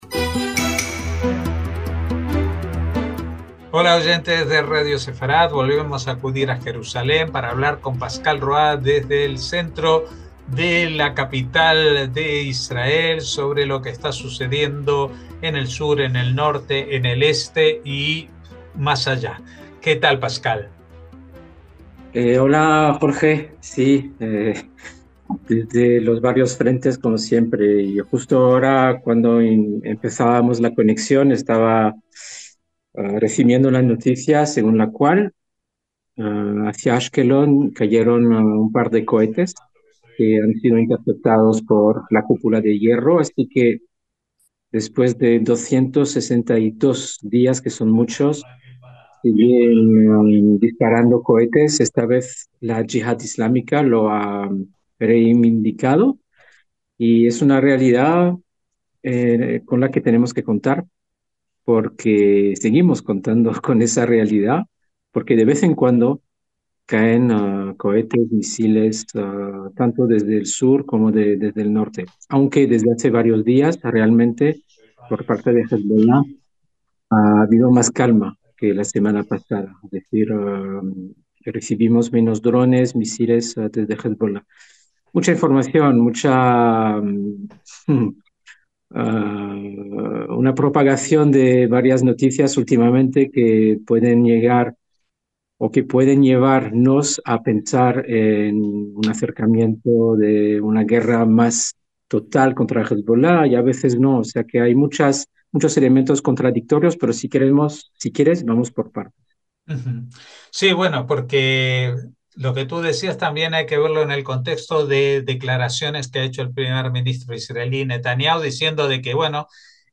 NOTICIAS CON COMENTARIO A DOS